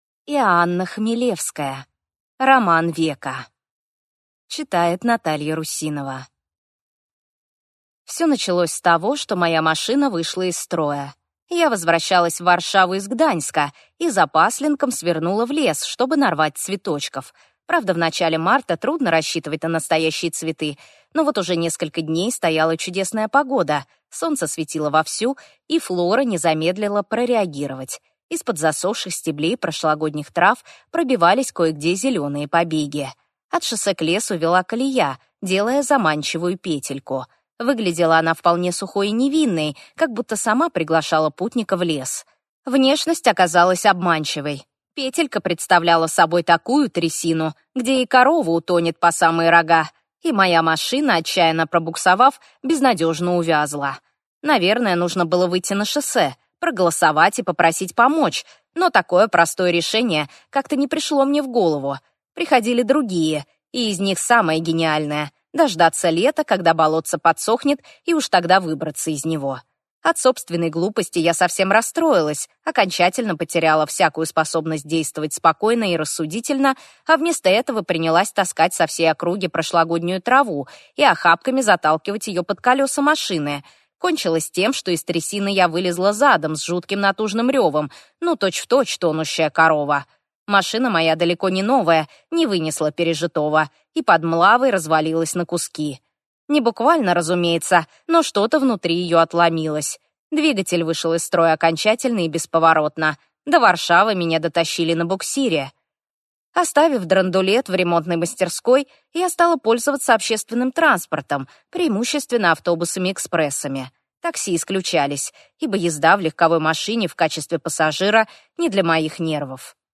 Аудиокнига Роман века | Библиотека аудиокниг